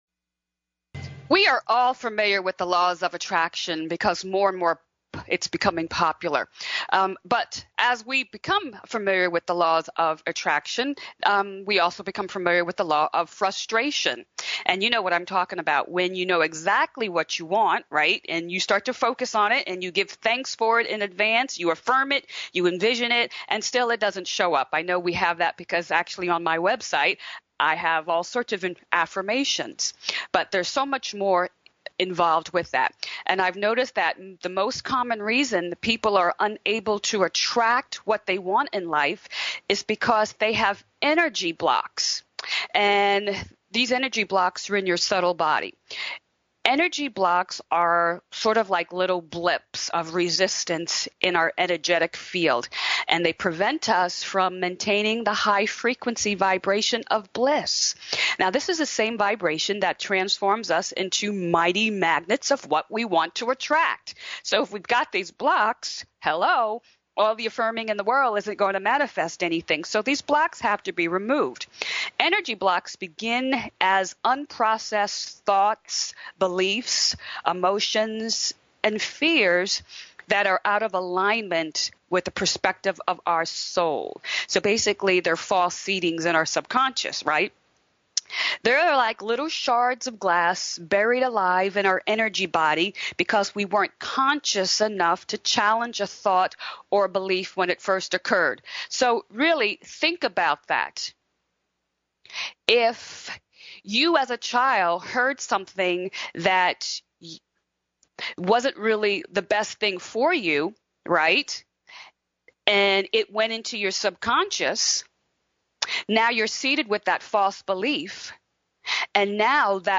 Each week you’re invited to join in on our upbeat discussion as we look deeper into spiritual based principles that can change your life.